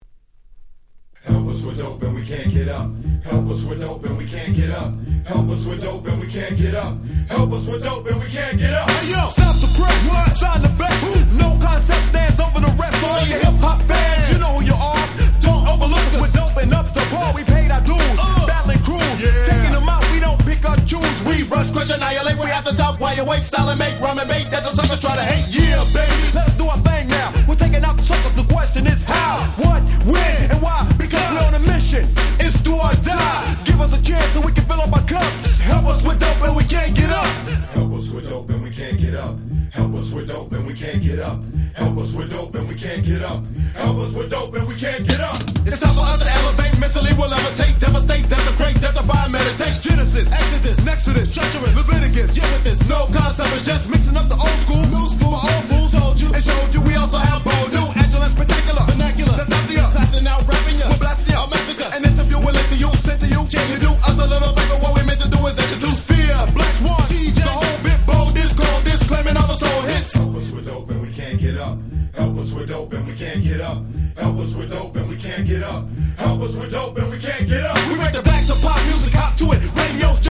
92' Nice West Coast Hip Hop !!
BPM速めの疾走感のあるBeatがイケイケな
(Vocal Mix)